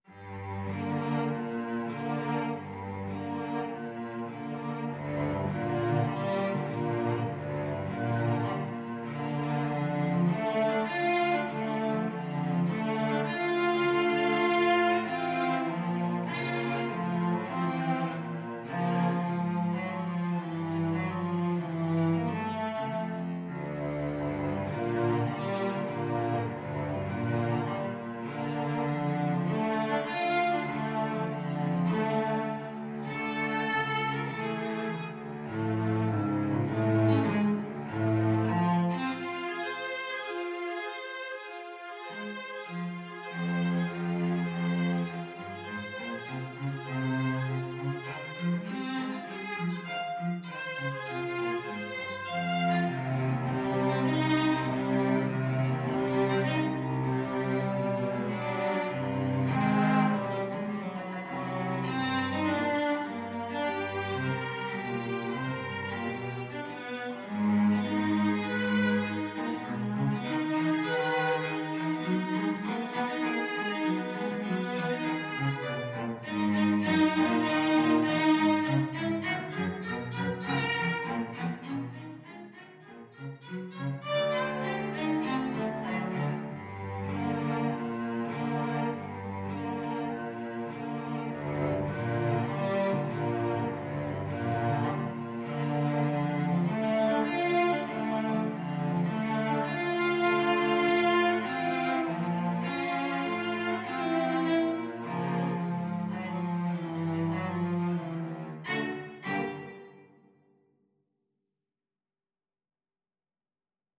4/4 (View more 4/4 Music)
Cello Duet  (View more Advanced Cello Duet Music)
Classical (View more Classical Cello Duet Music)